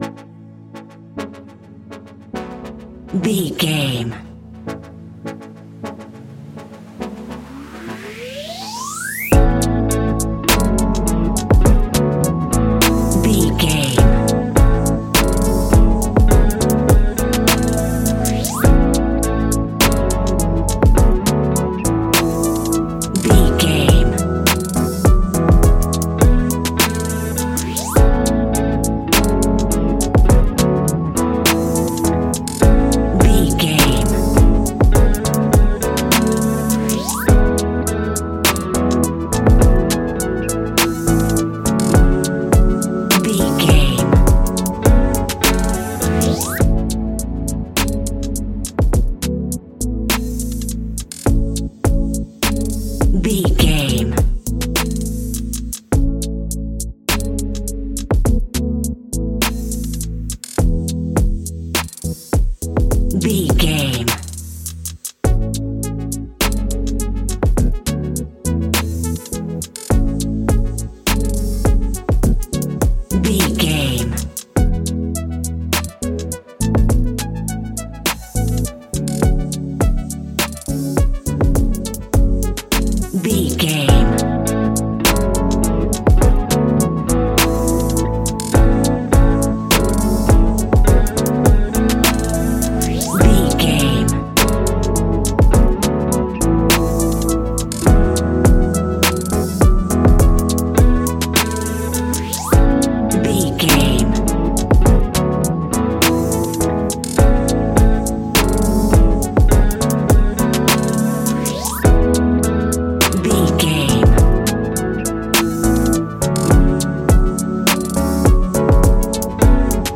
Ionian/Major
laid back
Lounge
sparse
new age
chilled electronica
ambient
atmospheric
instrumentals